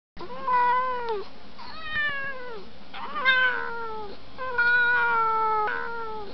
cat.mp3